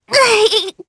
Viska-Vox_Damage_jp_01.wav